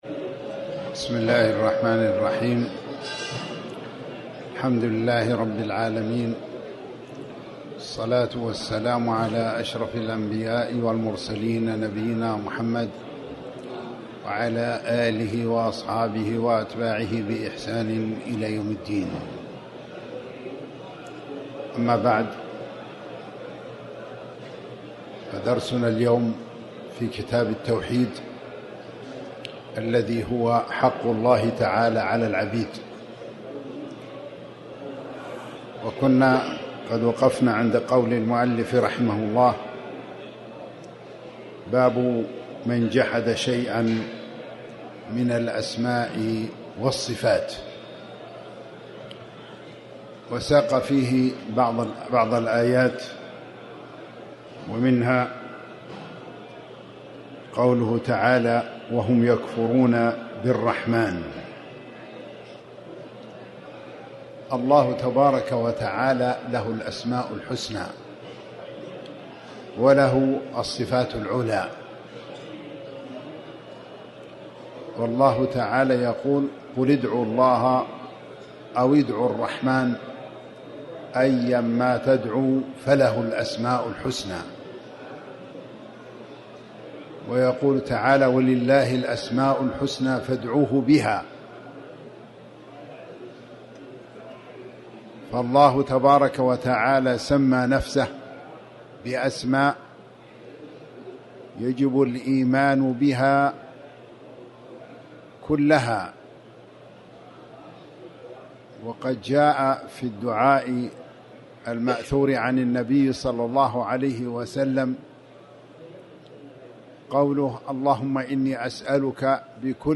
تاريخ النشر ٢١ رمضان ١٤٤٠ هـ المكان: المسجد الحرام الشيخ